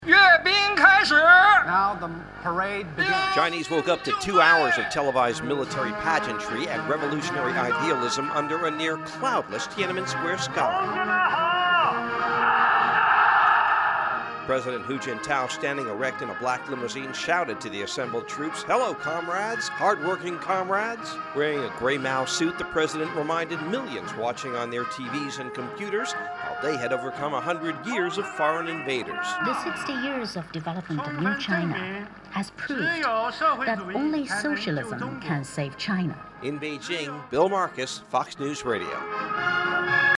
REPORTS FROM BEIJING.